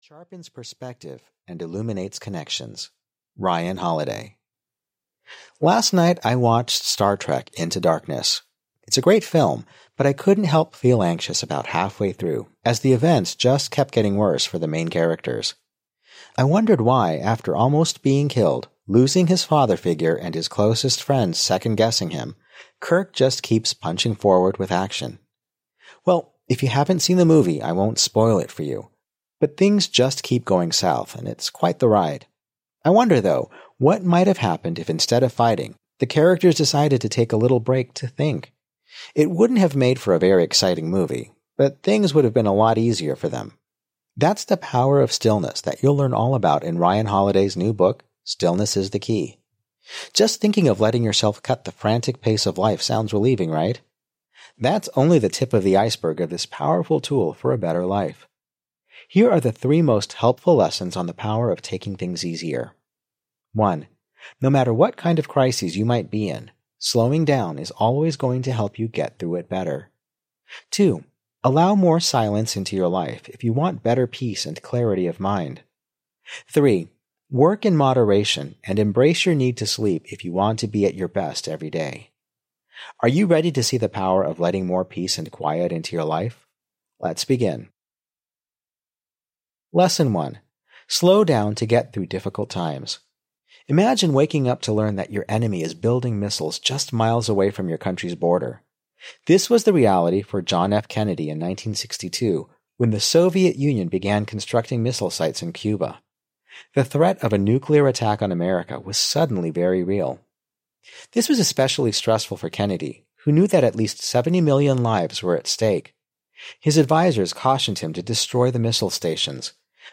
Audio kniha
Ukázka z knihy